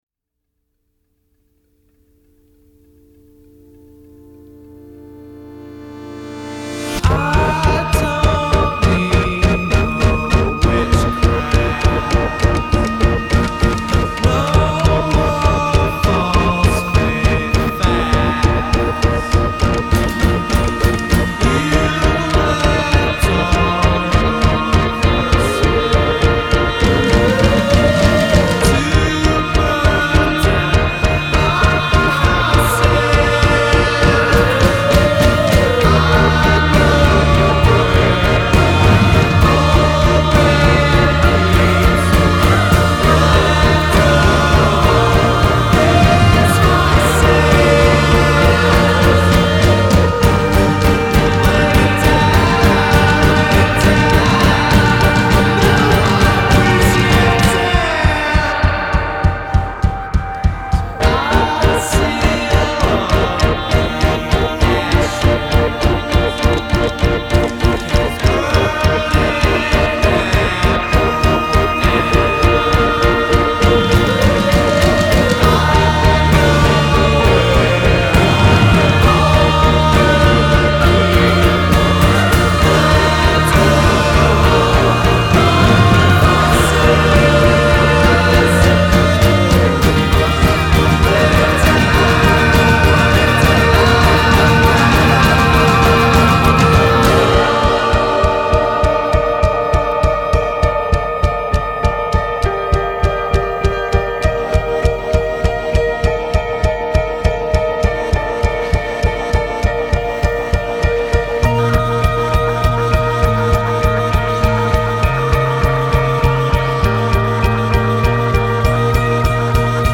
dreamy, swirling pop music